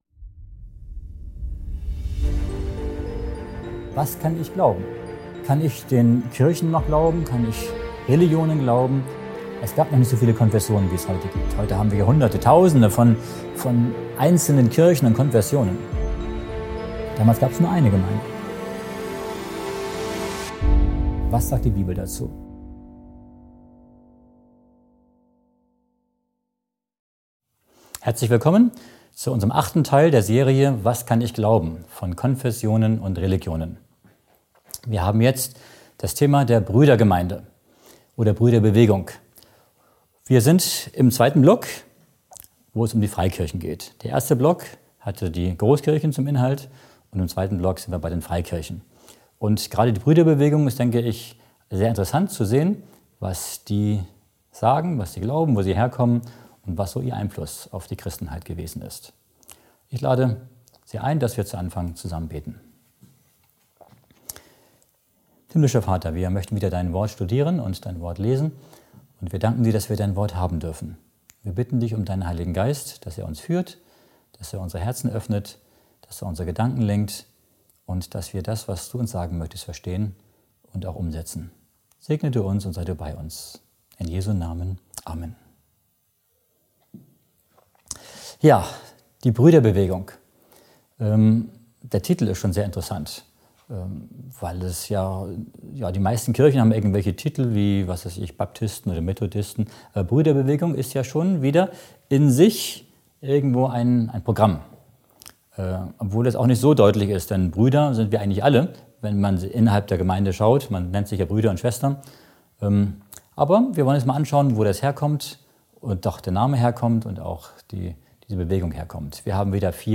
Ein faszinierender Vortrag über die Brüdergemeinde und deren Wurzeln im 19. Jahrhundert. Entdecken Sie die Unterschiede zwischen offenen und geschlossenen Brüdern, die Herausforderungen der Glaubenslehren und die biblische Basis der Bewegung.